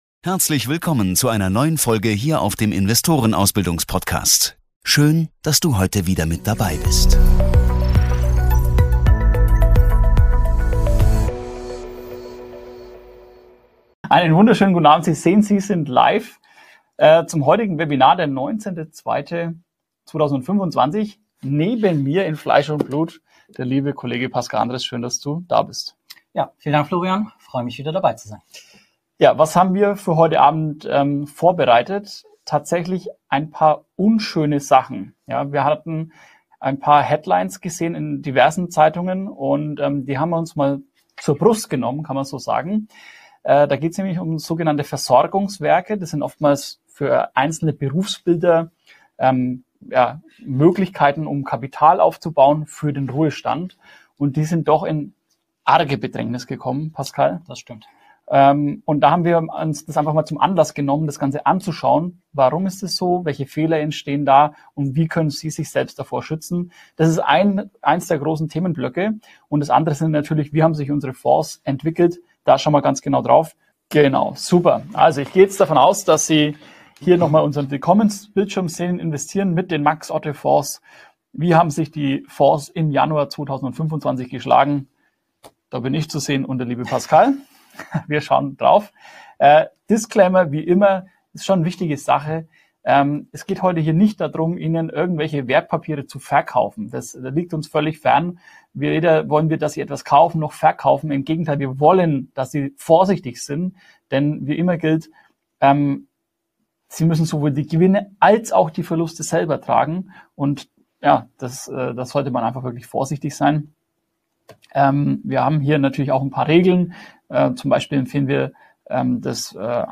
Zudem zeigt der Vortrag die Chancen und Risiken der Altersvorsorge auf. Bisher sichere Pensionkassen für Freiberufler sind in Schieflage geraten....